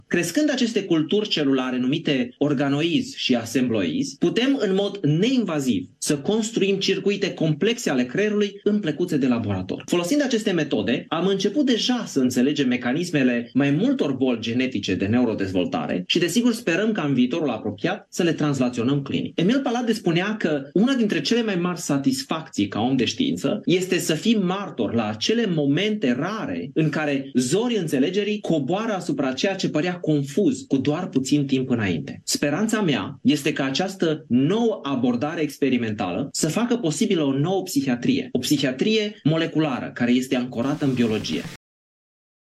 Cercetătorul a transmis un mesaj video cu ocazia evenimentului.